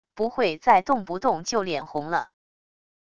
不会再动不动就脸红了wav音频生成系统WAV Audio Player